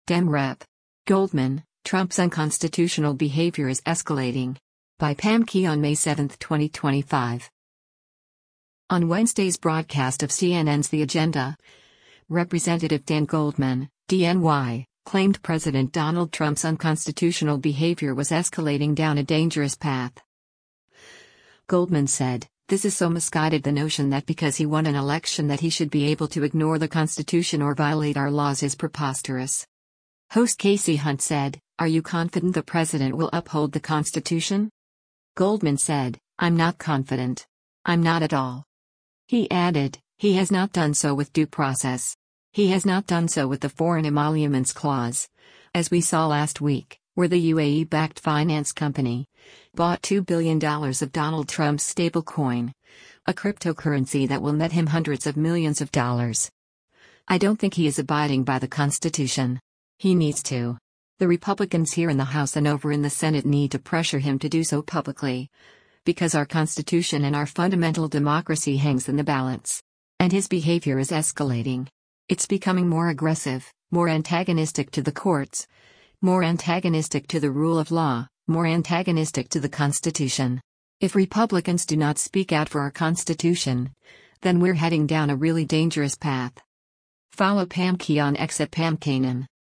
On Wednesday’s broadcast of CNN’s “The Agenda,” Rep. Dan Goldman (D-NY) claimed President Donald Trump’s unconstitutional behavior was escalating  down a dangerous path.